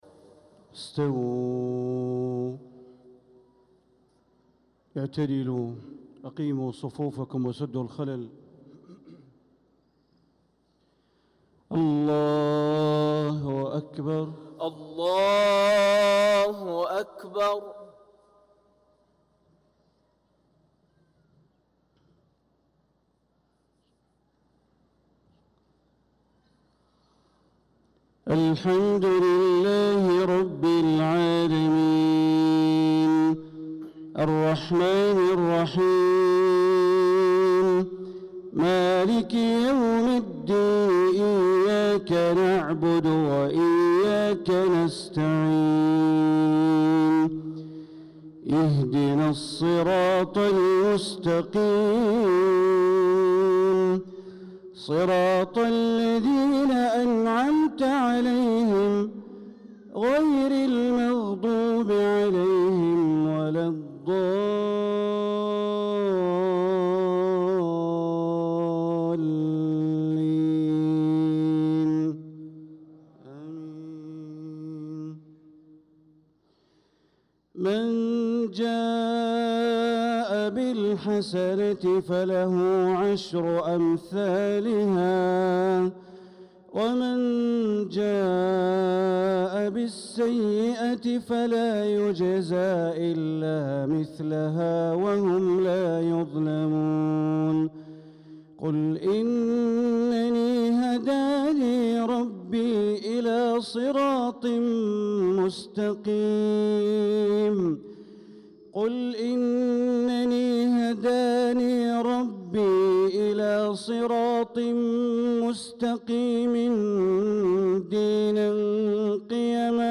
صلاة المغرب للقارئ بندر بليلة 9 ذو الحجة 1445 هـ
تِلَاوَات الْحَرَمَيْن .